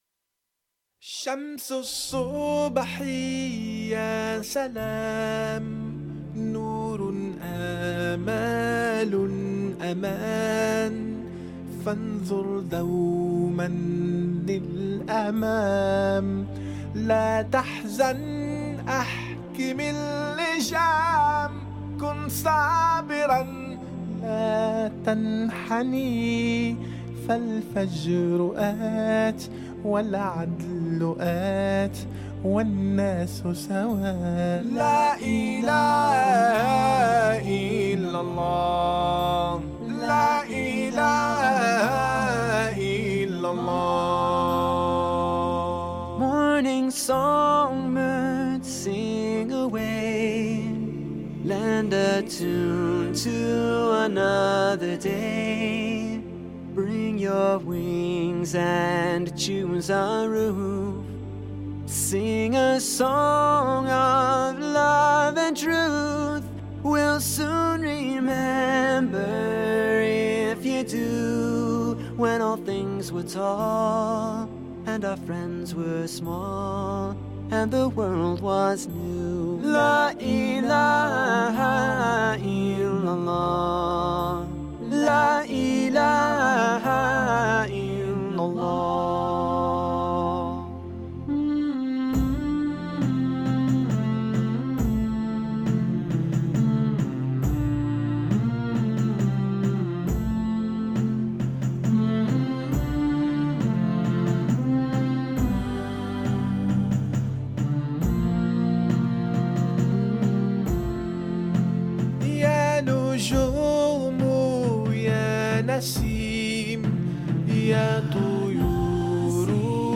Música [Nashid]